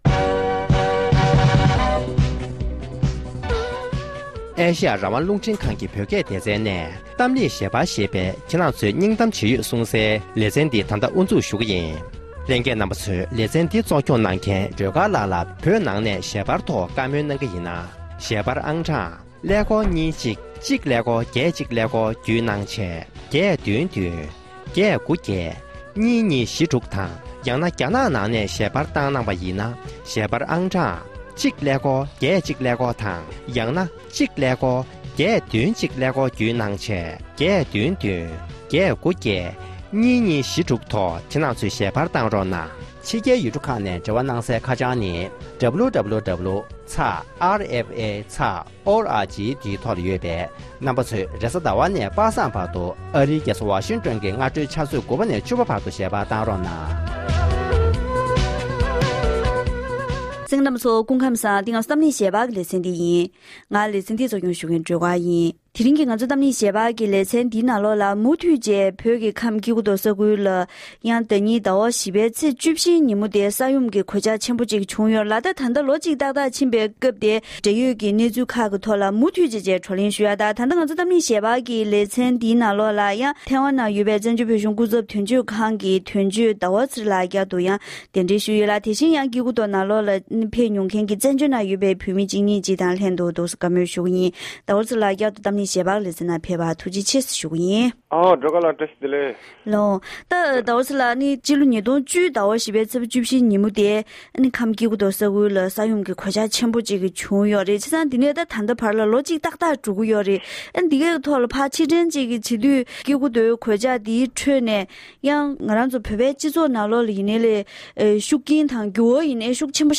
༄༅༎དེ་རིང་གི་གཏམ་གླེང་ཞལ་པར་ལེ་ཚན་ནང་སྐྱེ་རྒུ་མདོ་ས་གནས་སུ་ས་ཡོམ་བྱུང་སྟེ་ལོ་ངོ་གཅིག་འཁོར་བའི་སྐབས་དེར་ས་ཡོམ་གྱི་གོད་ཆག་ཁྲོད་ནས་གནོད་འཚེ་ཕོག་ཡུལ་མི་དམངས་ནས་བོད་མི་སྤྱི་ཡོངས་ཀྱི་དབར་ལ་ཤུགས་རྐྱེན་ཇི་འདྲ་བྱུང་མིན་ཐོག་འབྲེལ་ཡོད་མི་སྣ་ཁག་ཅིག་དང་ལྷན་དུ་བཀའ་མོལ་ཞུས་པ་ཞིག་གསན་རོགས་གནང་།